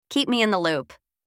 Primeiro, vamos ouvir a pronúncia deste chunk: